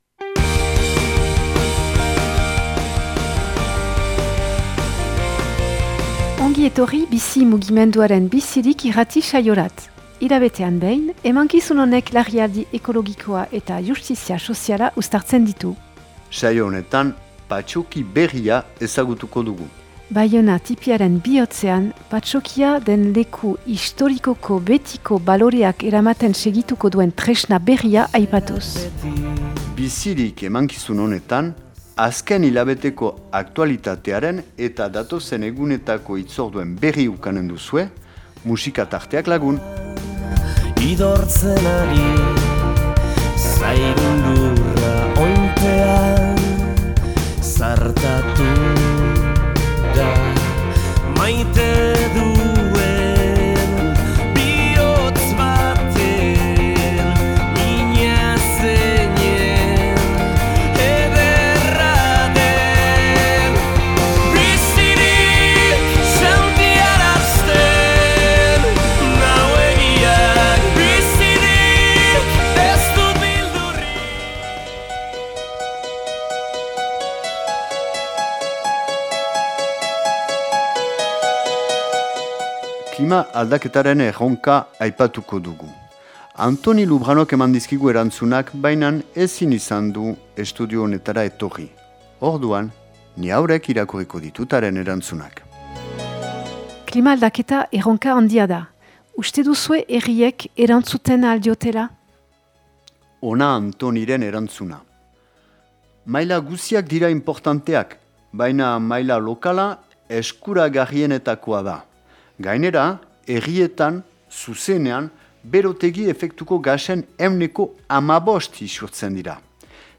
Enregistrement émission de radio en langue basque #127